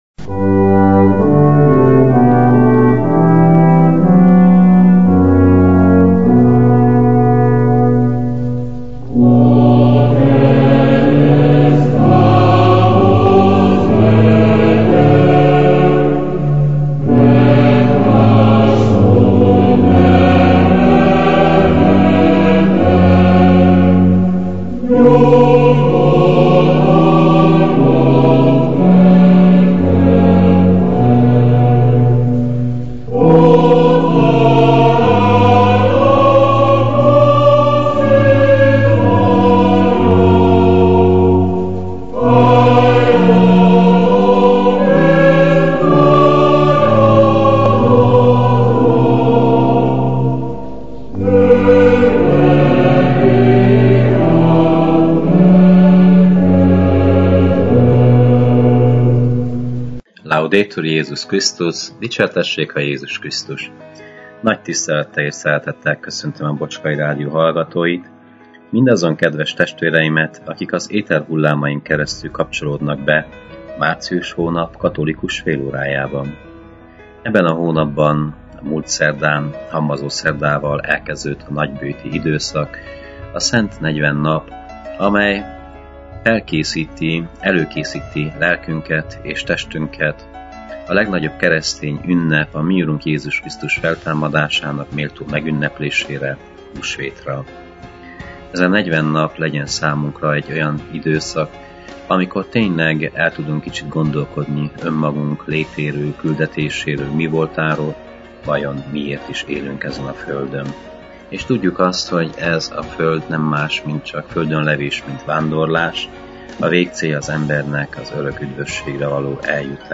Igét hirdet
a clevelandi Szent Imre Katolikus Templomból.